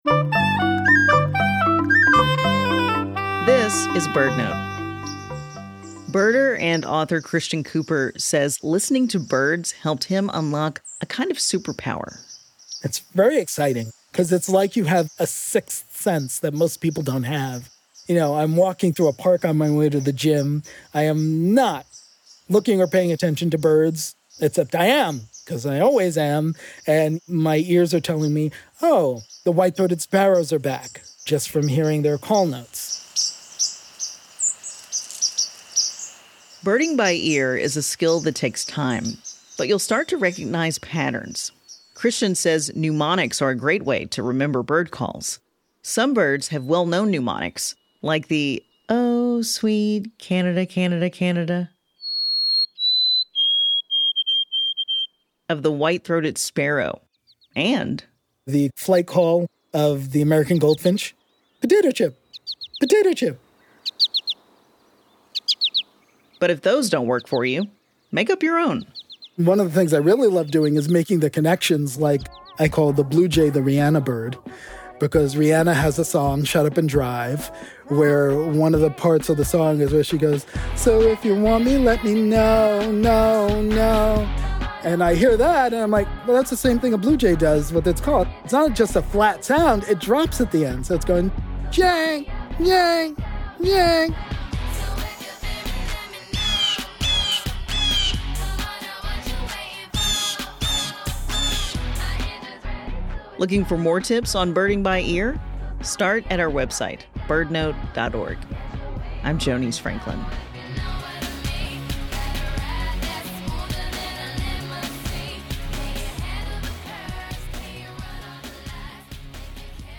BirdNote is sponsored locally by Chirp Nature Center and airs live every day at 4 p.m. on KBHR 93.3 FM.